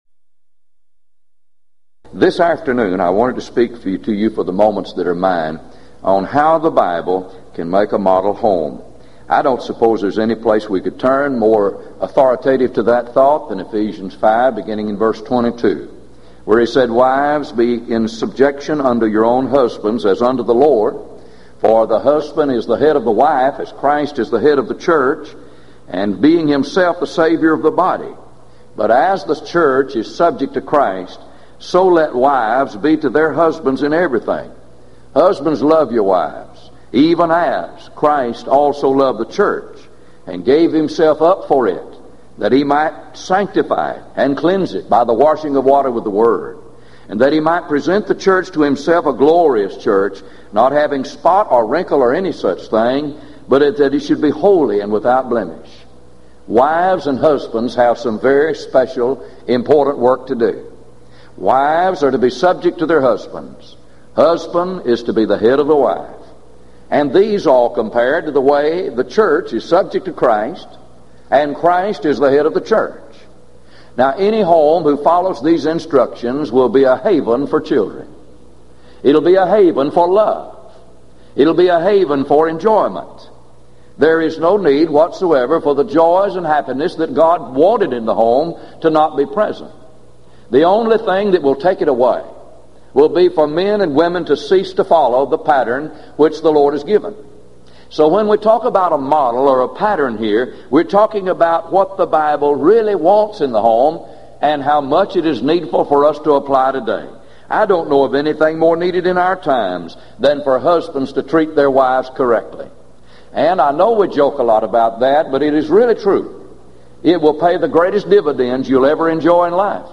Event: 1996 Gulf Coast Lectures Theme/Title: Lively Issues On The Home And The Church